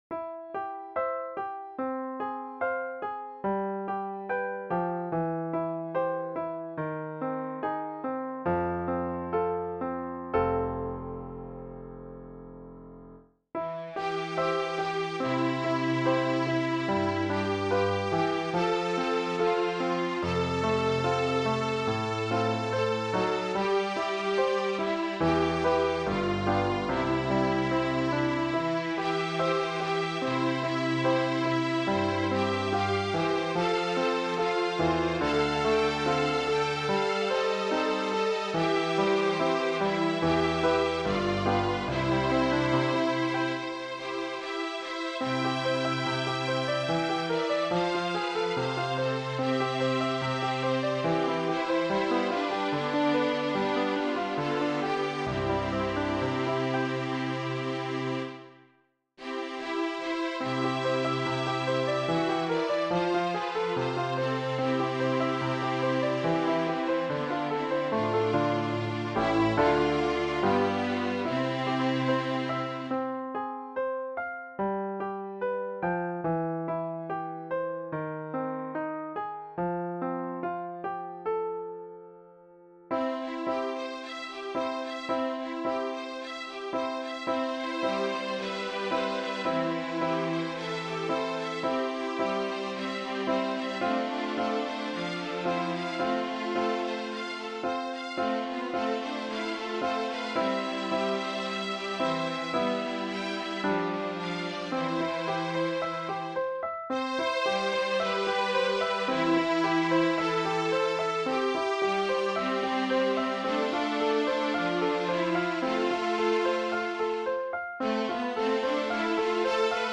String Trio